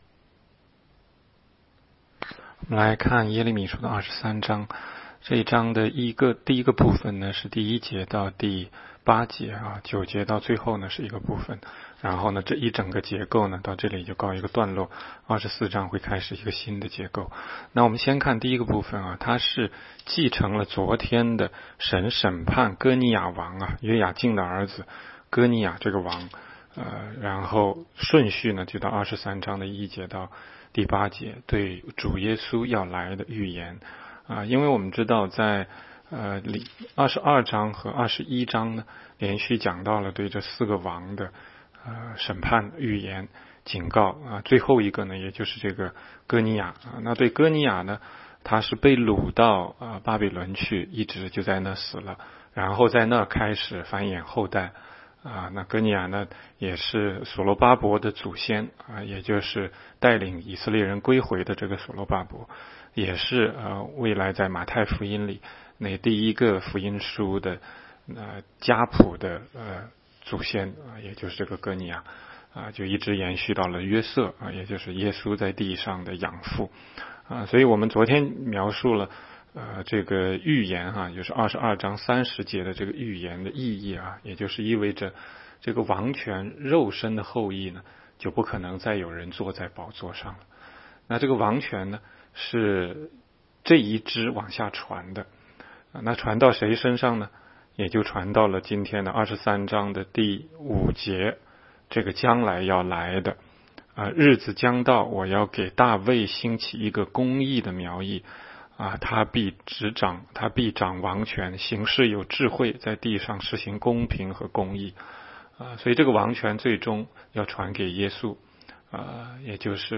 16街讲道录音 - 每日读经 -《耶利米书》23章